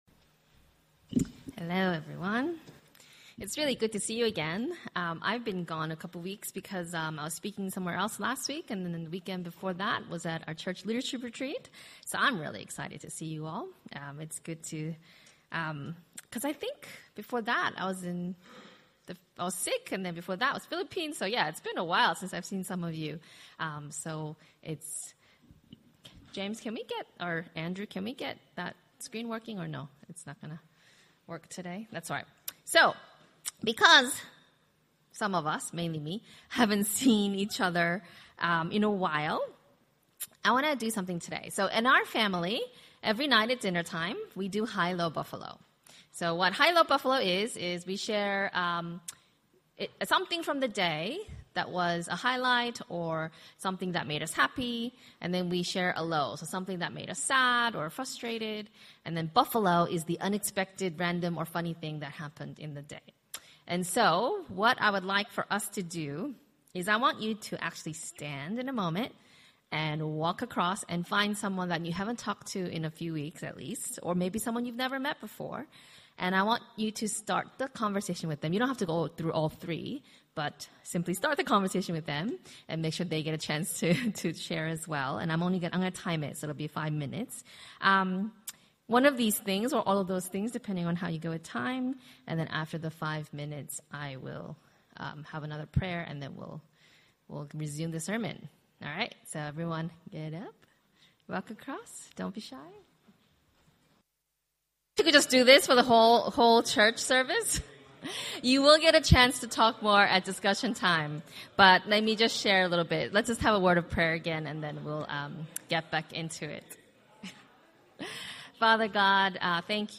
SDA Sermons